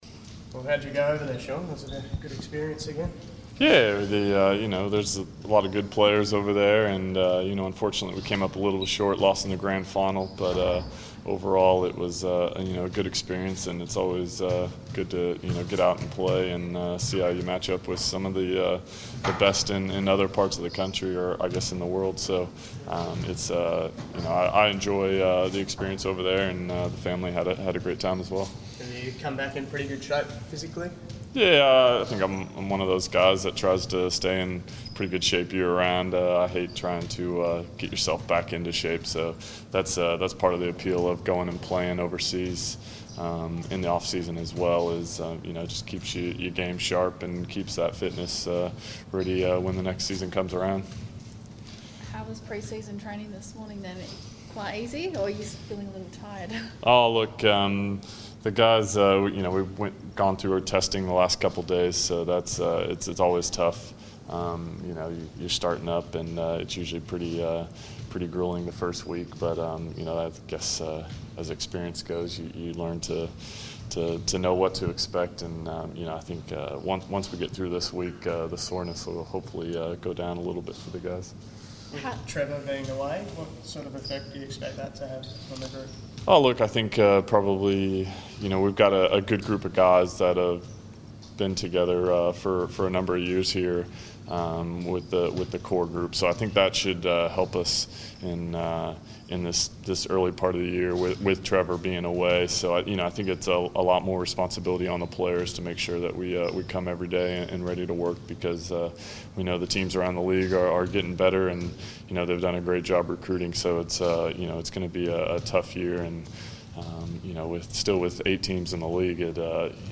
media conference